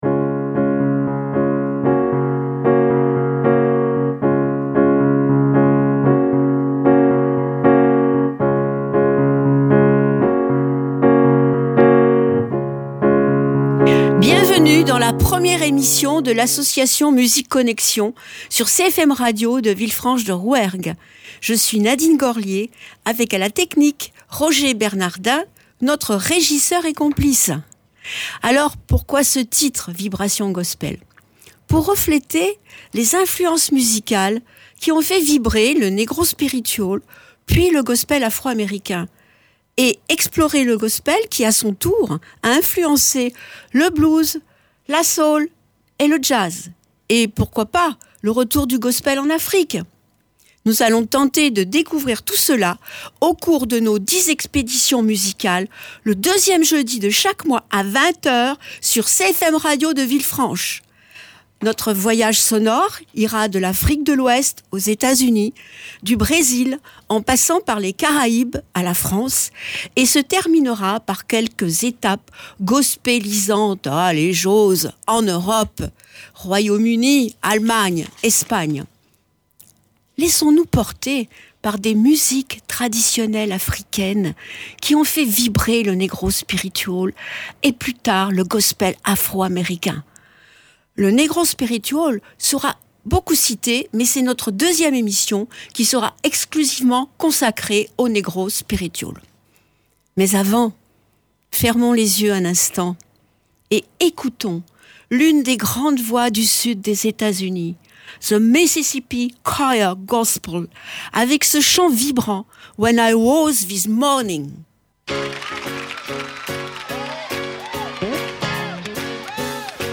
Nous découvrons les structures des Chorales traditionnelles africaines qui ont donné vie au Negro Spiritual puis au Gospel Afro-Américain. Et surtout, nous entendons des chants envoûtants qui touchent nos cœurs.